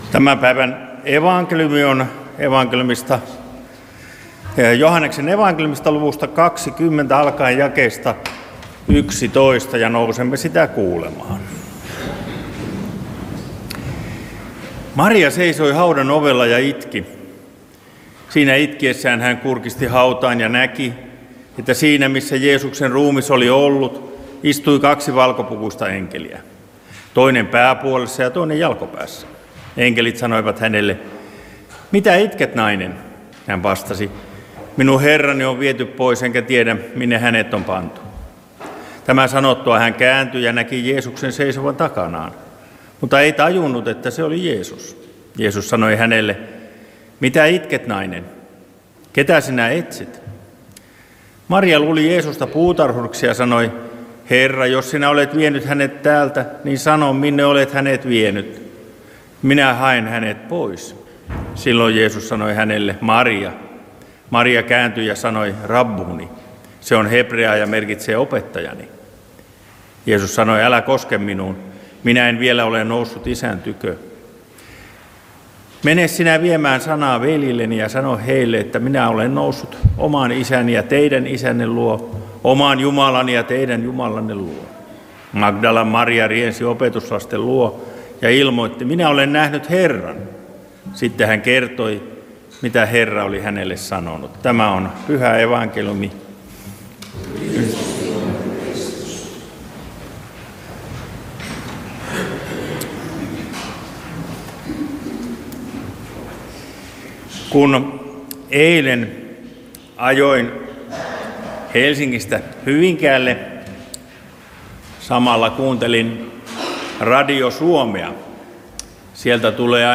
Lahti